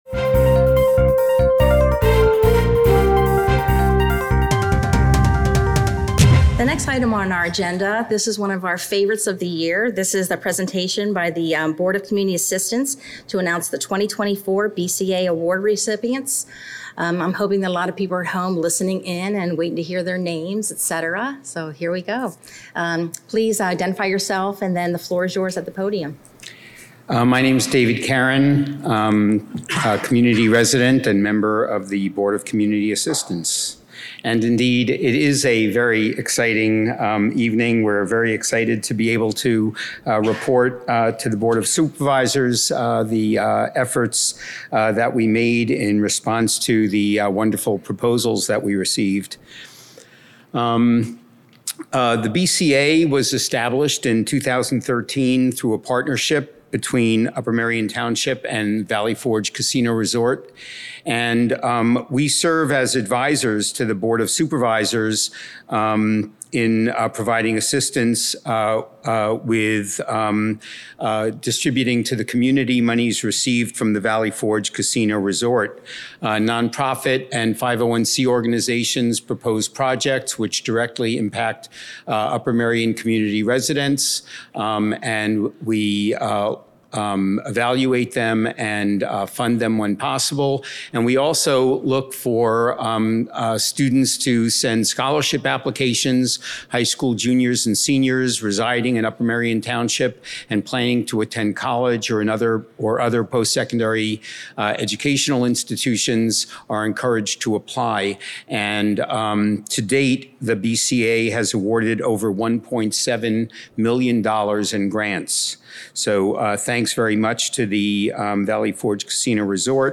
Presentation by the Board of Community Assistance to Announce 2024 BCA Award Recipients.